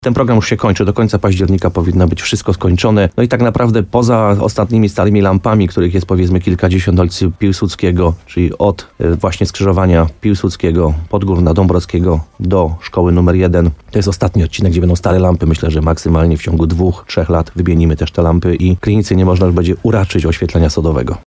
Piłsudskiego kilkadziesiąt, czyli od skrzyżowania z ulicami Podgórną i Dąbrowskiego do Szkoły Podstawowej nr 1, to jest ostatni odcinek, gdzie będą stare lampy – mówił burmistrz Krynicy-Zdroju Piotr Ryba.